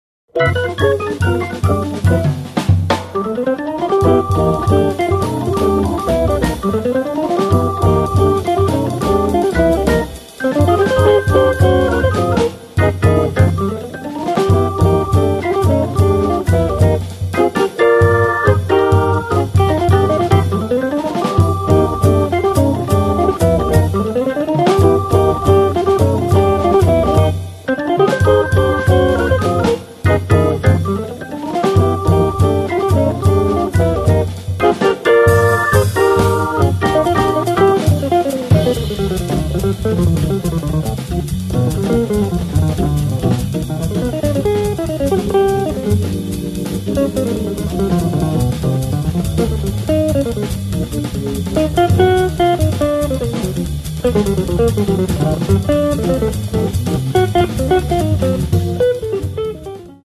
guitar
hammond B3 Organ
drums